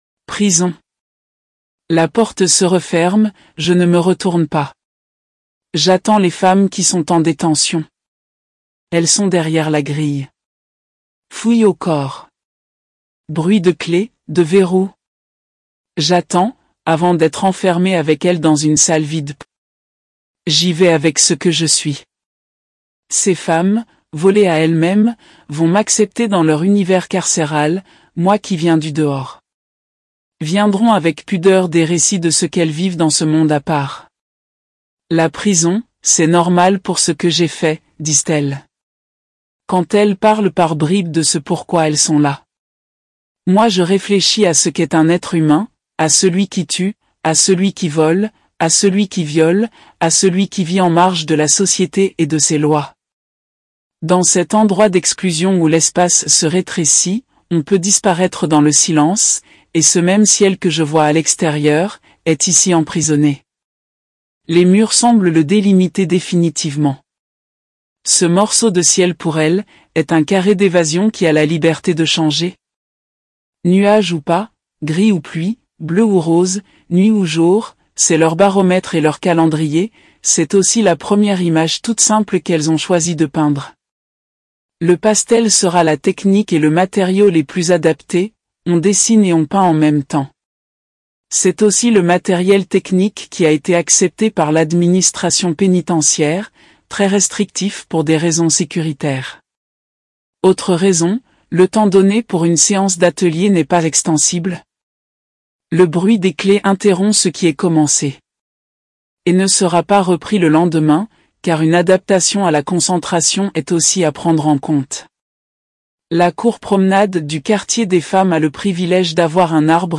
Le son du texte ci-dessous a été généré par un outil de synthèse vocale (TTS) pour un rendu audio « réaliste »